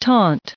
added pronounciation and merriam webster audio
798_taunt.ogg